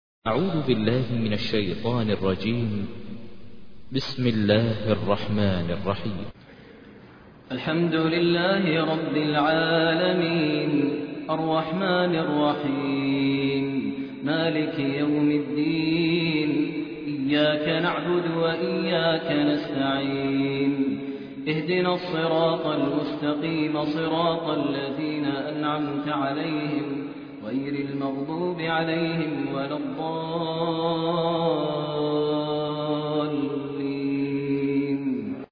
تحميل : 1. سورة الفاتحة / القارئ ماهر المعيقلي / القرآن الكريم / موقع يا حسين